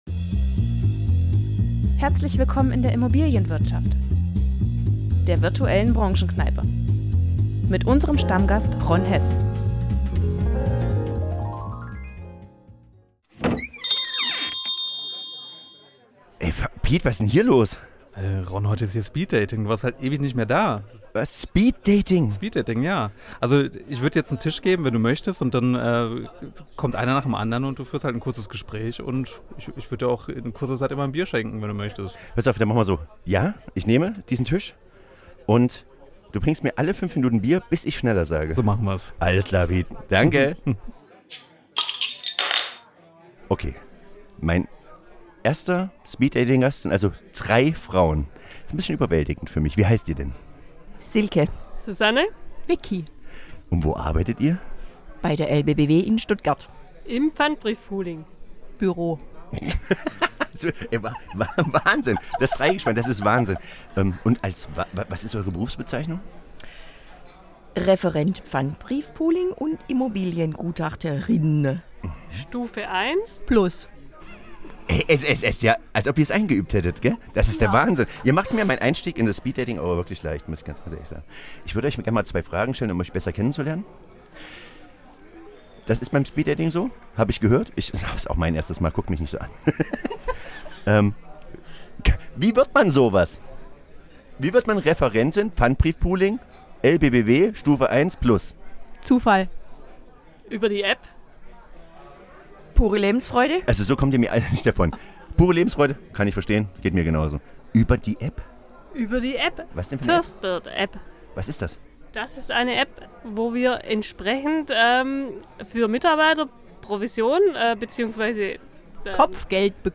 Die Soundeffekte kommen von Pixabay.